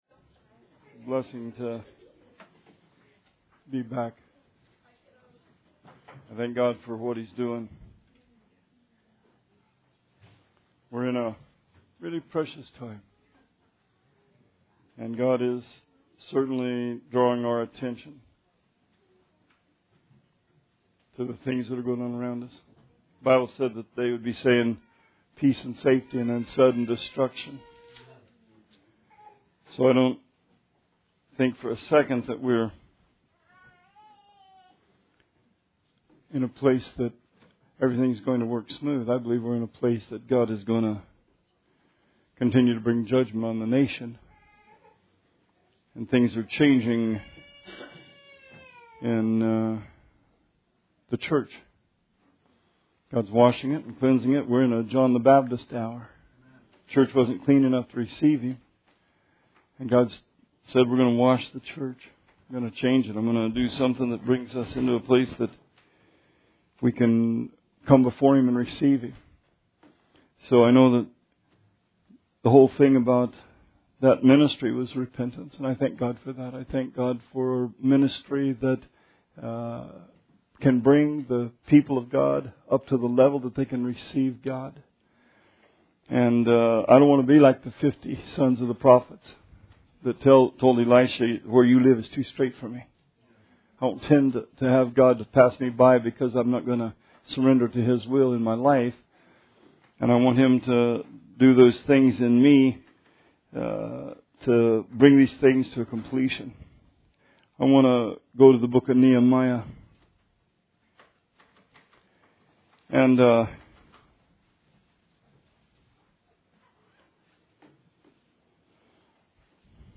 Sermon 8/13/17